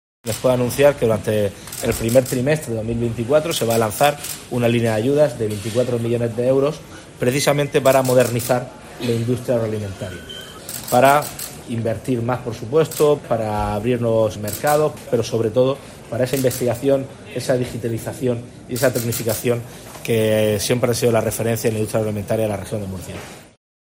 López Miras dio a conocer esta iniciativa durante la clausura de la Asamblea General de la Agrupación de Empresas de Alimentación de Murcia, Alicante y Albacete (Agrupal).